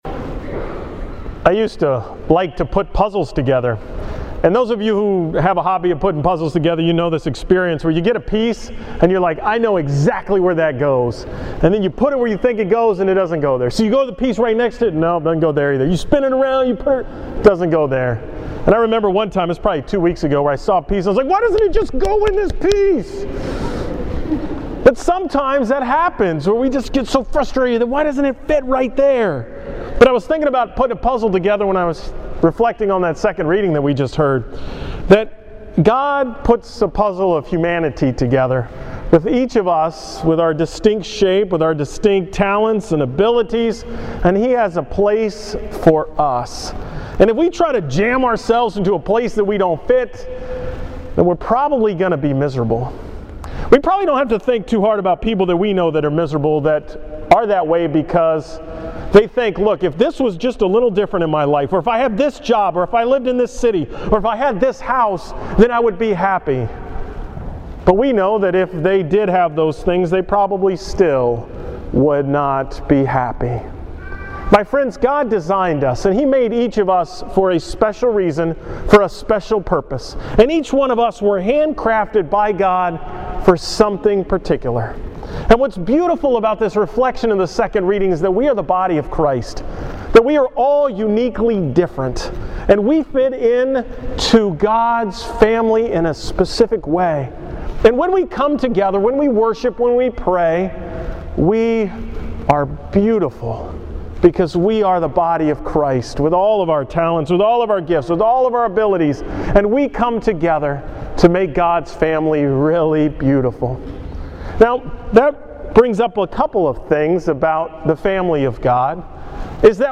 Homily on Sunday, January 27th